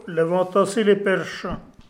Localisation Nieul-sur-l'Autise
Catégorie Locution